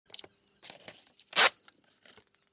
Звуки оплаты банковской картой, контактная и бесконтактная оплата, через Apple Pay и Google Pay
8. Оплата картой и кассирша отрывает чек с терминала